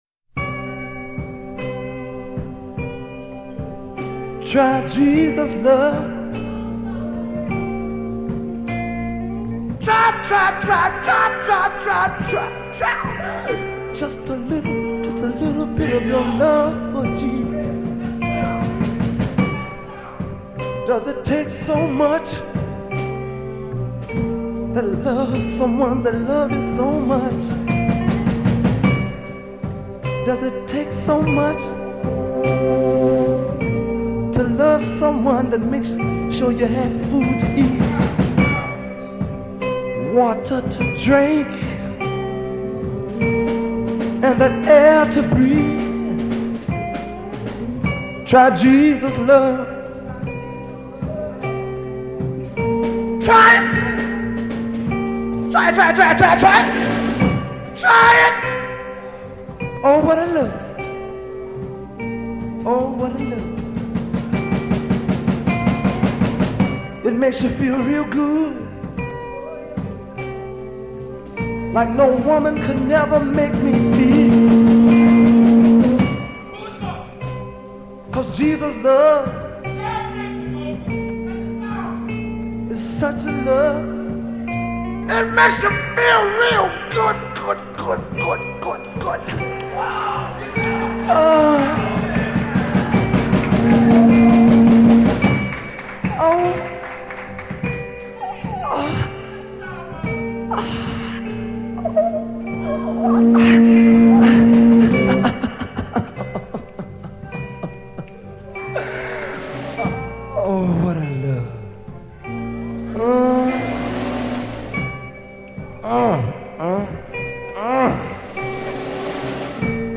a religious song